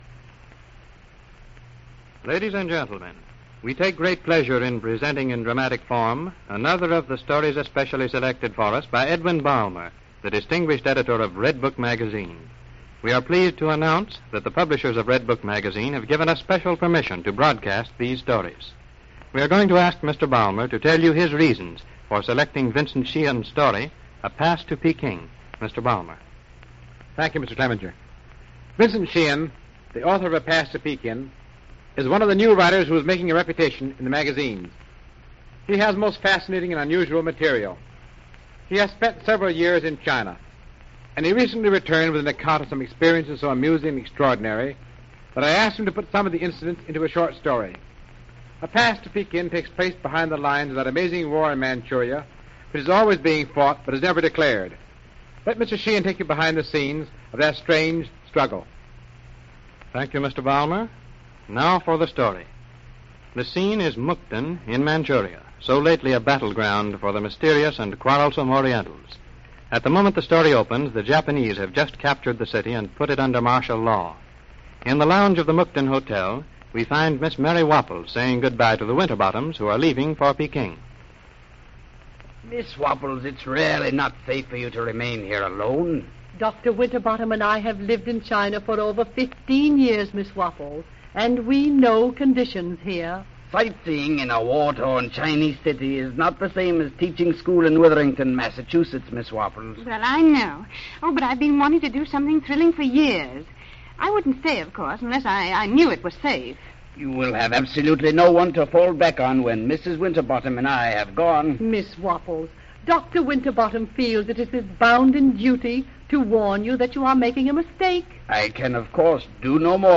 Airing on June 16, 1932, this episode is a testament to the storytelling prowess that radio dramas held, especially during the challenging times of the Great Depression.